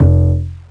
cch_bass_one_shot_wooden_F.wav